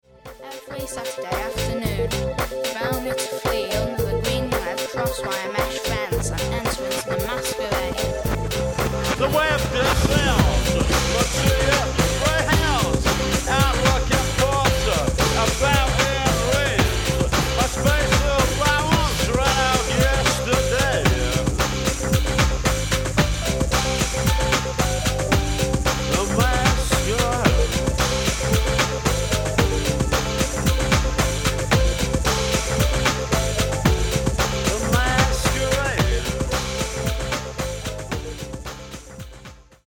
It was quite a tongue-twister and took a few takes.